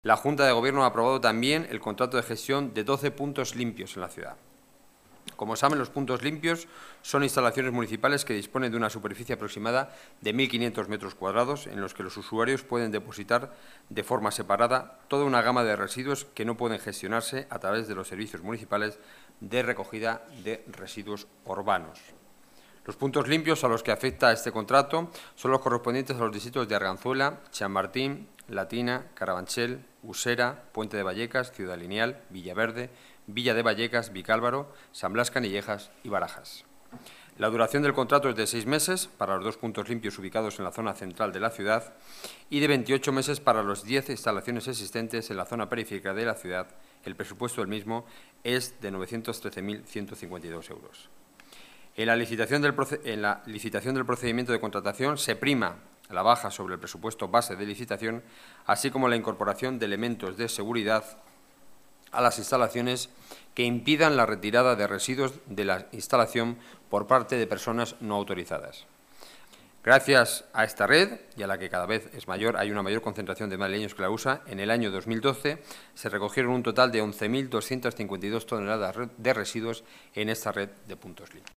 Nueva ventana:Declaraciones portavoz Gobierno Ciudad Madrid, Enrique Núñez: más de 900.000 euros para puntos limpios